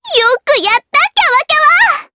Annette-Vox_Victory_jp.wav